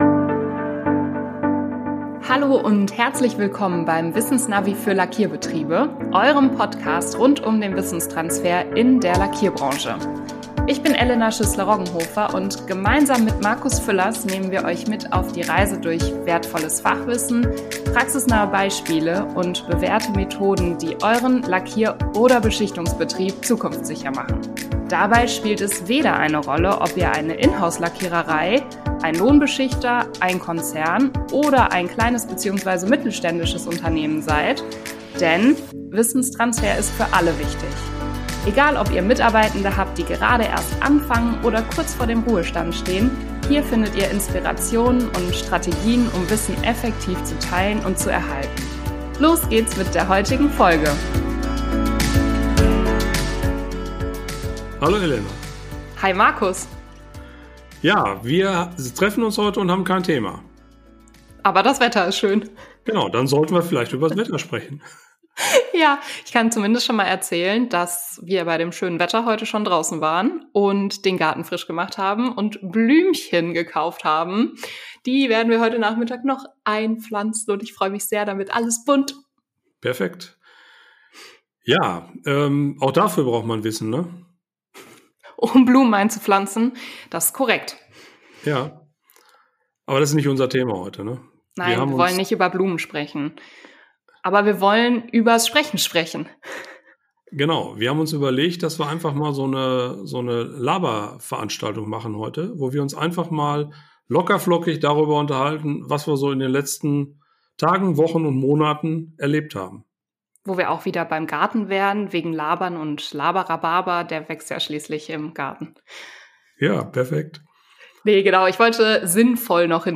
Laber-Rhabarber - einfach mal drauflos quatschen - locker, ehrlich, ungeschnitten ~ Das Wissensnavi für Lackierbetriebe Podcast